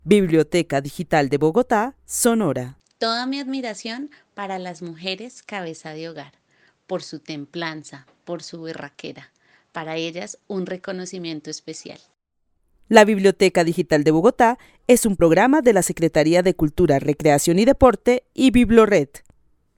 Narración oral de una mujer que vive en la ciudad de Bogotá y admira a las mujeres cabezas de hogar por su templanza y berraquera. El testimonio fue recolectado en el marco del laboratorio de co-creación "Postales sonoras: mujeres escuchando mujeres" de la línea Cultura Digital e Innovación de la Red Distrital de Bibliotecas Públicas de Bogotá - BibloRed.
Narrativas sonoras de mujeres